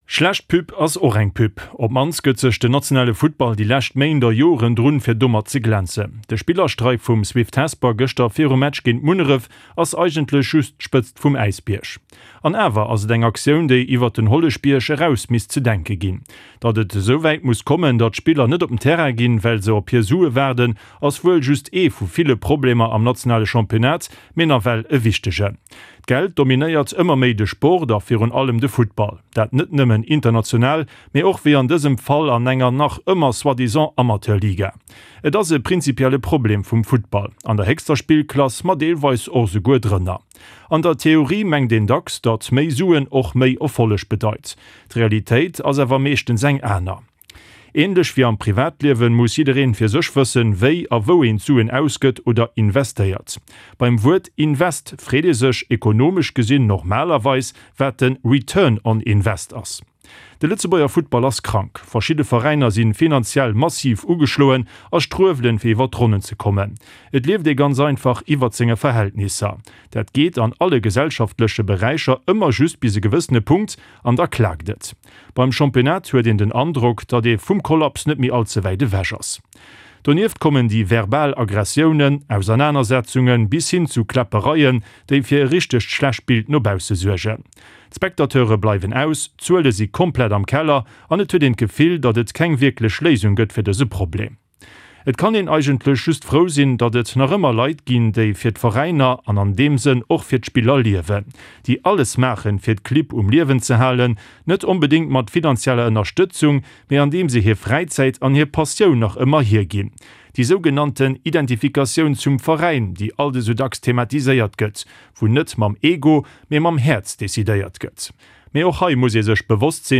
Ee Commentaire